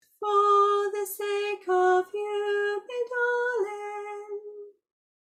Hum the tune and thing about which way it goes…..